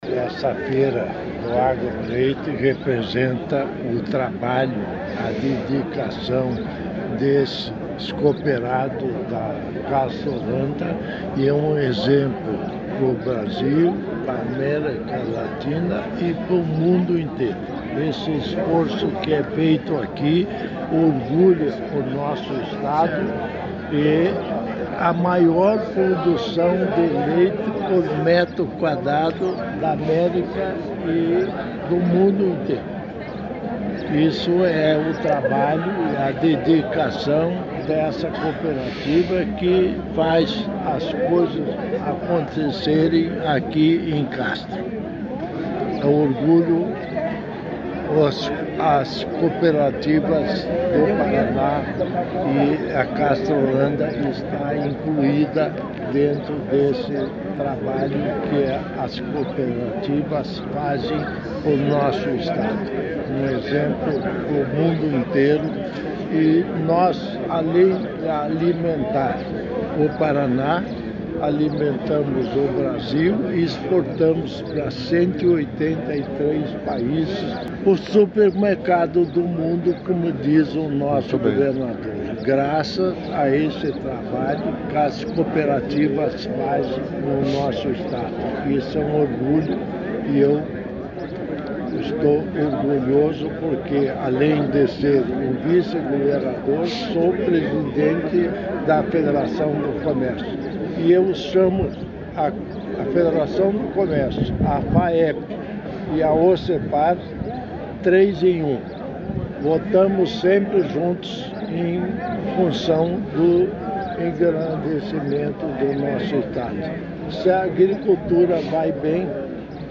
Sonora do vice-governador Darci Piana sobre a Agroleite 2025